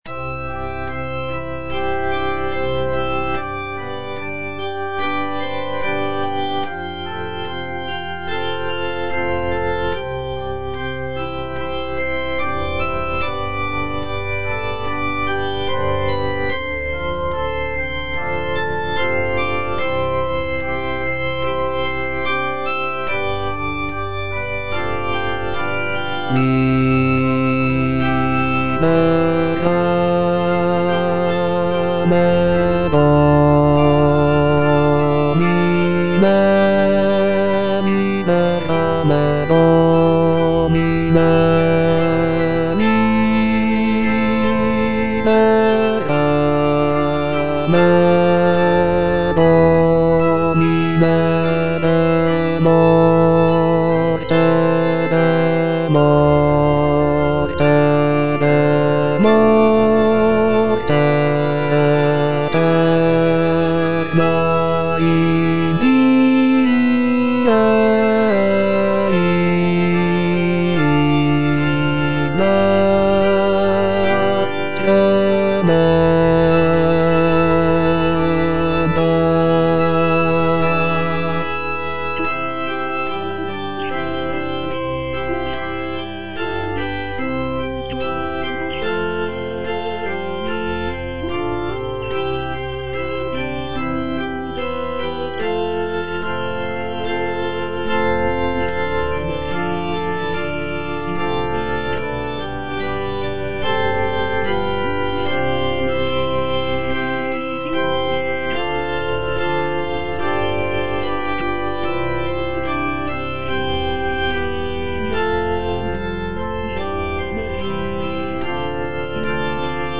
Bass (Voice with metronome) Ads stop
is a sacred choral work rooted in his Christian faith.